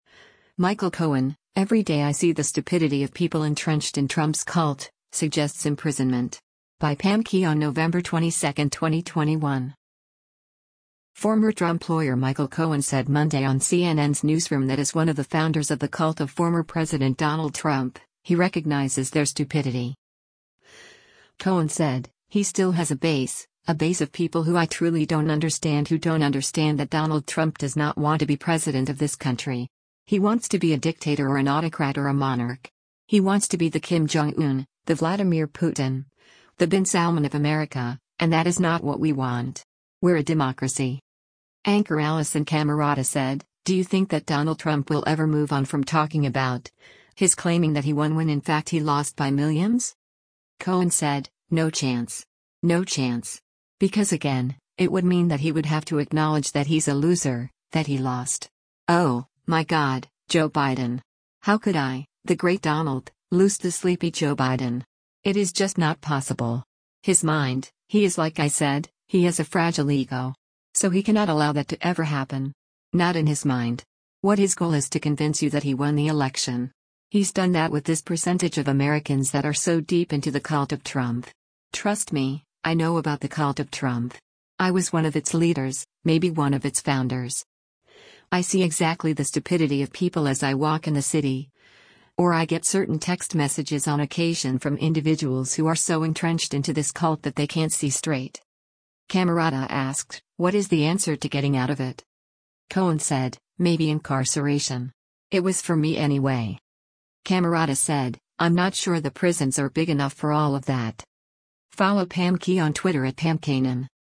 Former Trump lawyer Michael Cohen said Monday on CNN’s “Newsroom” that as one of the “founders” of the “cult” of former President Donald Trump, he recognizes their “stupidity.”